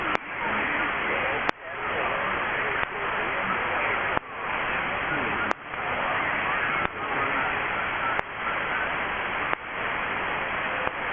Ouvea Island (OC-033) on 21MHz SSB